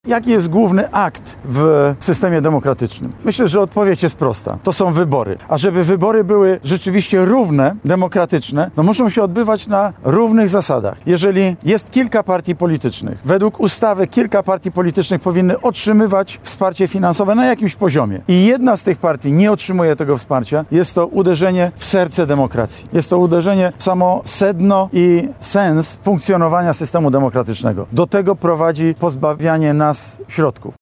Były premier Mateusz Morawiecki podczas wizyty w Lublinie skomentował decyzję Państwowej Komisji Wyborczej w sprawie subwencji dla Prawa i Sprawiedliwości.